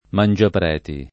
mangiapreti [ man J apr $ ti ] s. m.